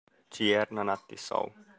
kiejtése) város Szlovákiában, a Kassai kerület Tőketerebesi járásában.